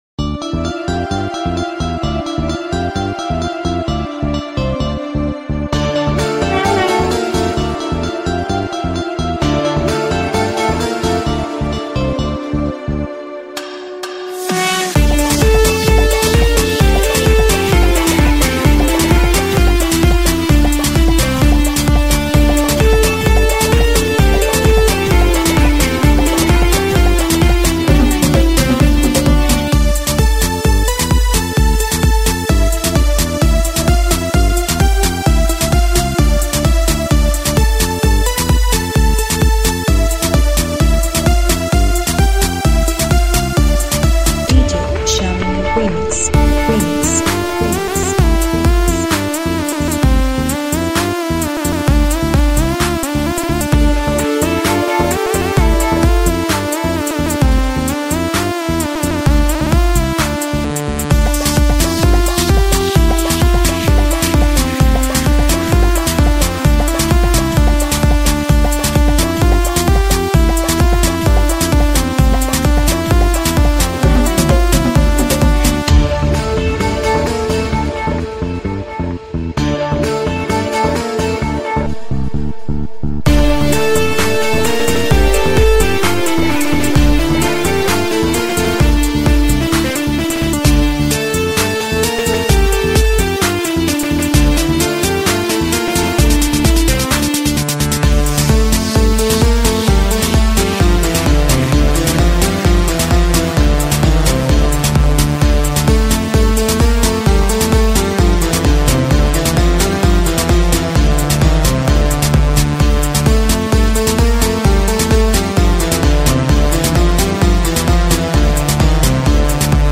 High quality Sri Lankan remix MP3 (80).
mixtape